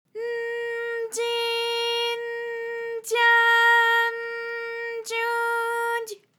ALYS-DB-001-JPN - First Japanese UTAU vocal library of ALYS.
dy_n_dyi_n_dya_n_dyu_dy.wav